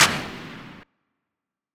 kits/Southside/Claps/Clp (Hendrix).wav at main
Clp (Hendrix).wav